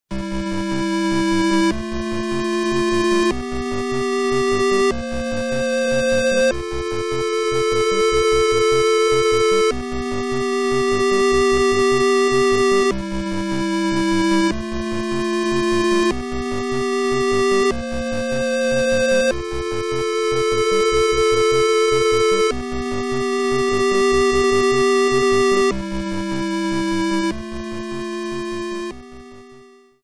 Trimmed to 30 seconds, applied fadeout